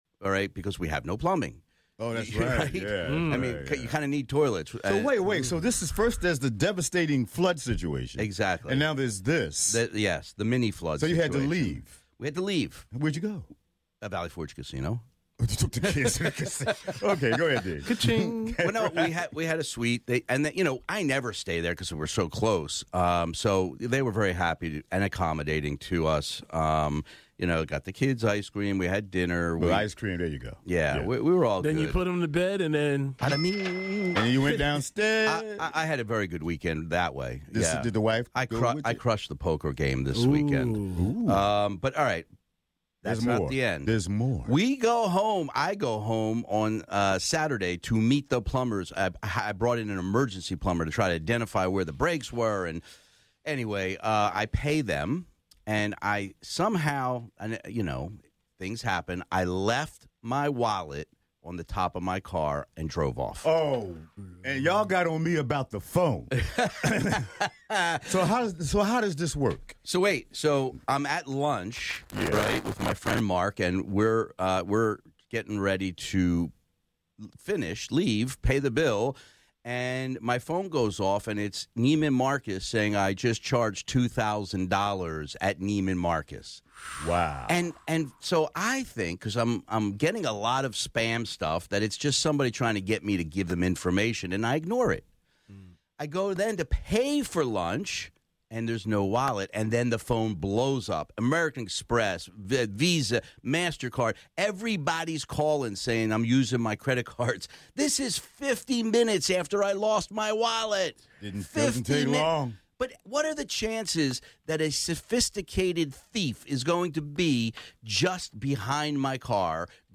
answers legal questions from callers